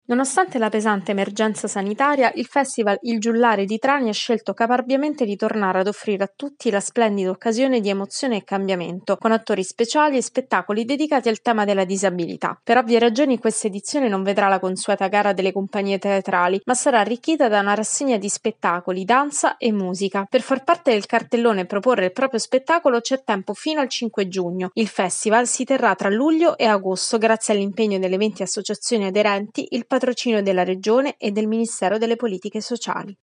Teatro contro ogni barriera: XIII edizione del festival nazionale il Giullare dedicato al tema della disabilità. Il servizio